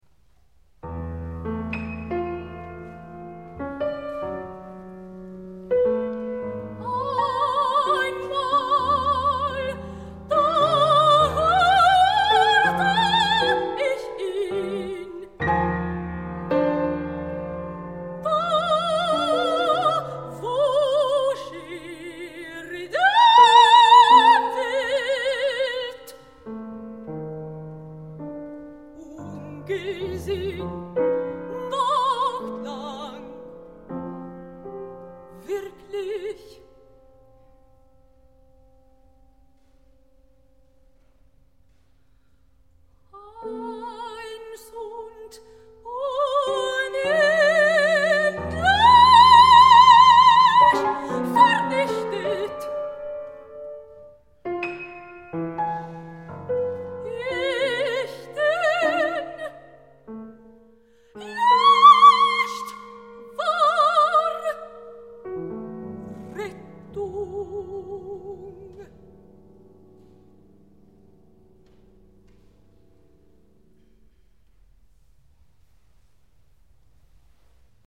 2002)for soprano and piano
for soprano and piano